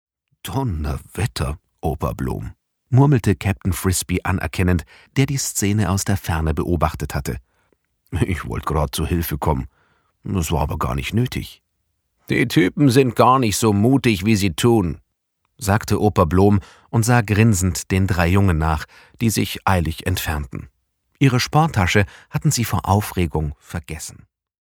sehr variabel
Mittel minus (25-45)
Bayrisch
Audiobook (Hörbuch)